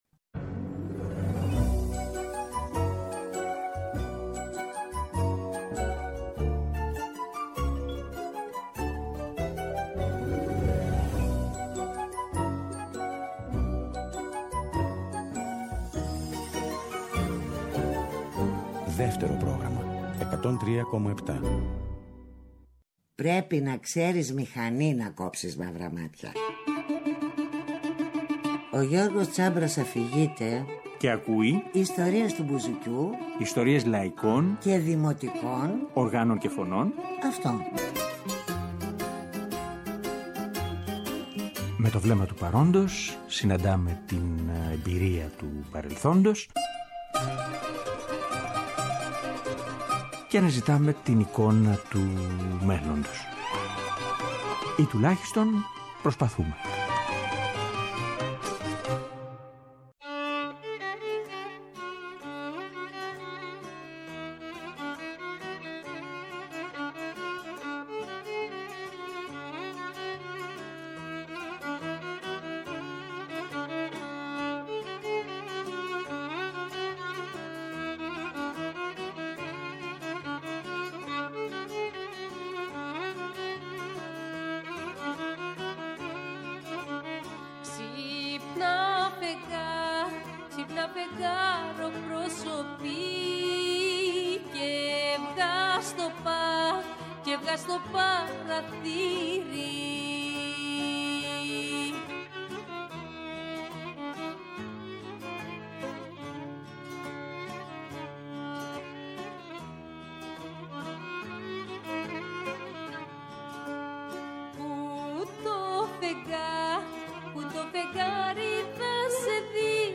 Βιολί
Τραγούδι
Ο Απεραθίτικος Σύλλογος Νάξου αποφασίζει να ηχογραφήσει έναν πρώτο δίσκο βινυλίου, στηριγμένος σε όργανα, φωνές και κυρίως στον τρόπο του χωριού του.